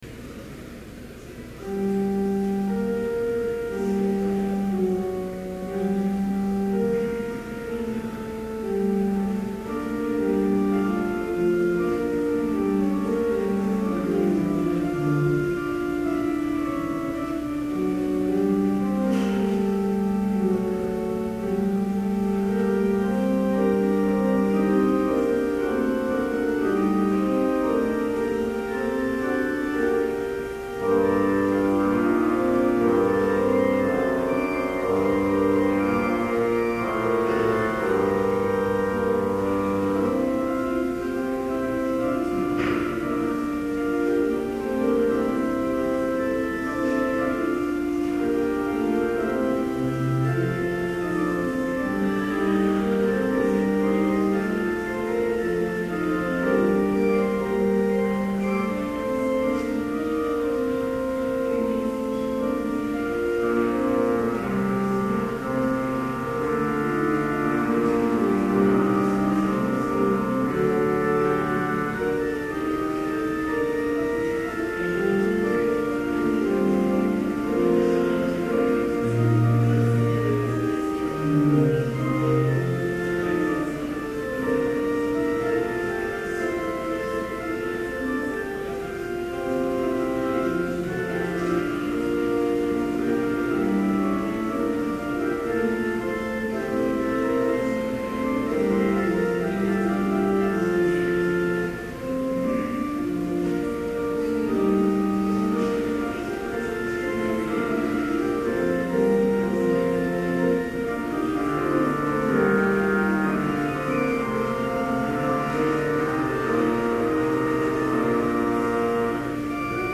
Complete service audio for Chapel - October 27, 2011